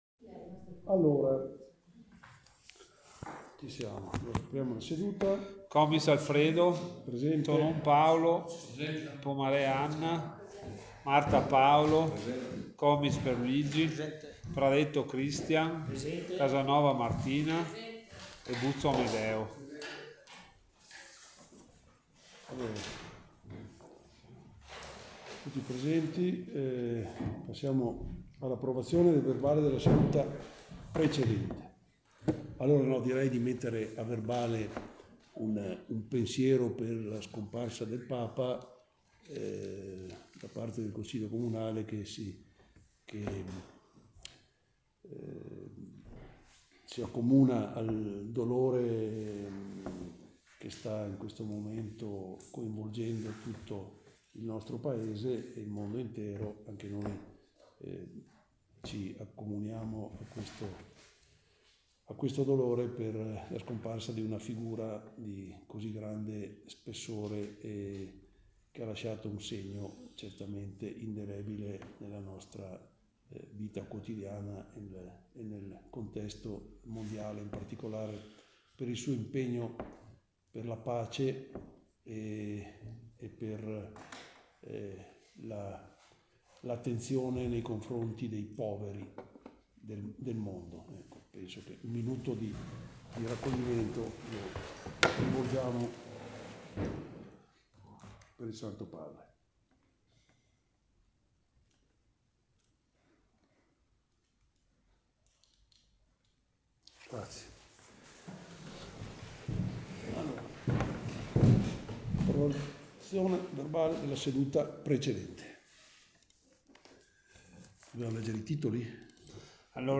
Registrazione Consiglio Comunale del 23_04_2025